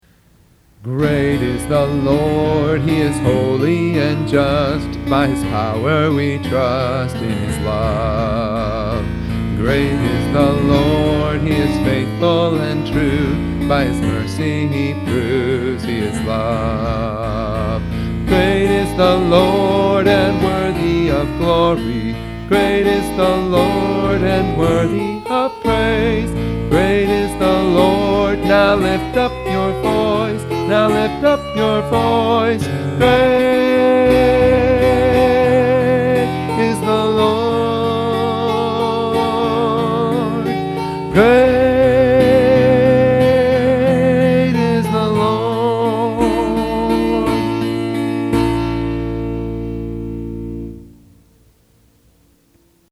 Transpose from A